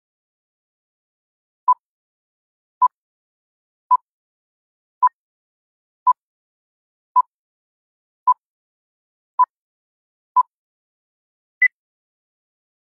Efek Countdown atau hitung mundur.